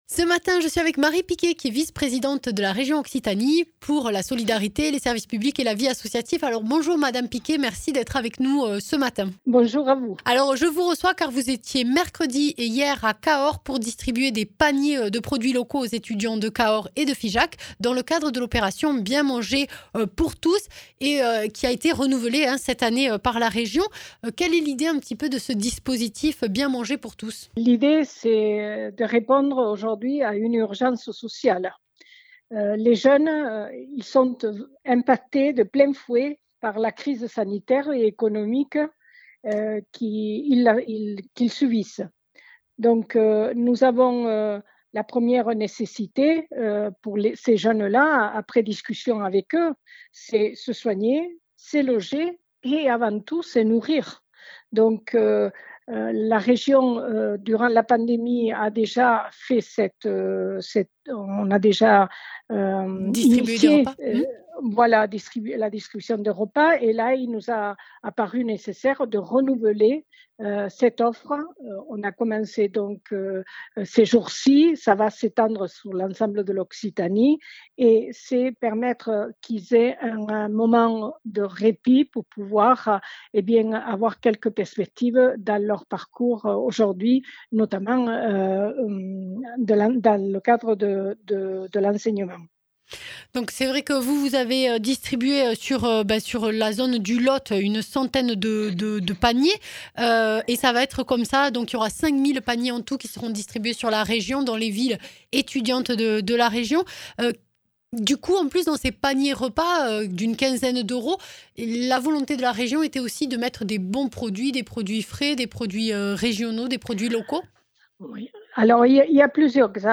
Interviews
Invité(s) : Marie Piqué, vice présidente solidarité, service publics et vie associative région Occitanie